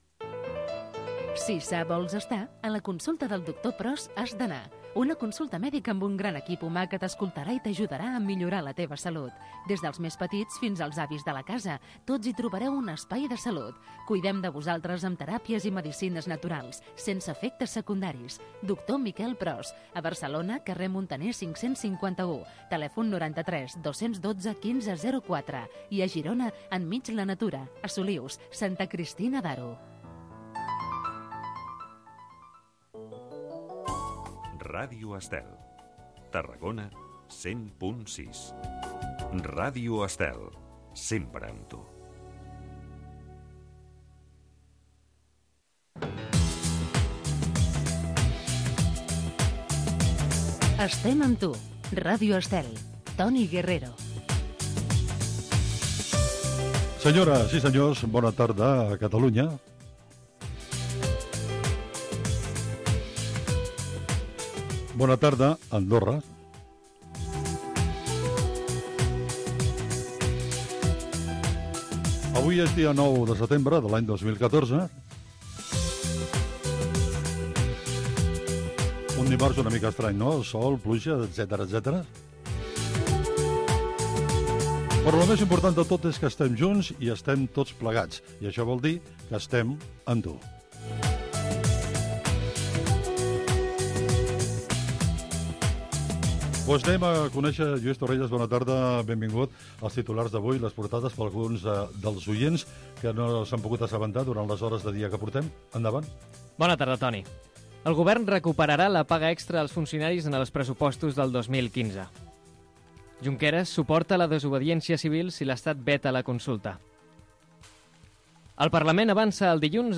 Publicitat, indicatius de l'emissora i del programa, presentació, data, titulars del dia, publicitat, la bona notícia, formes de participar al programa, anunci de la secció dedicada a la cuina de les àvies, tema musical, entrevista a la periodista Rosa Maria Calaf, publcitat i indicatiu Gènere radiofònic Entreteniment